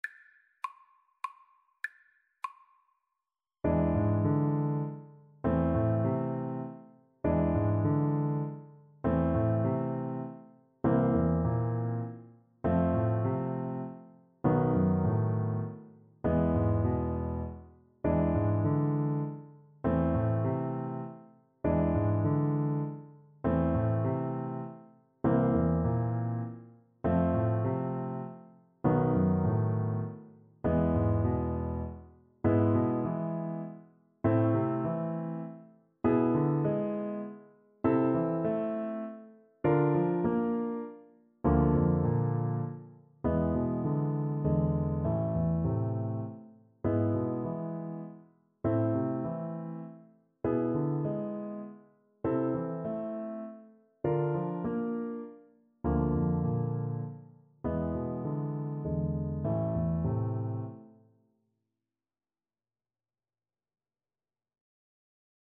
Etwas bewegt
3/4 (View more 3/4 Music)
Classical (View more Classical Viola Music)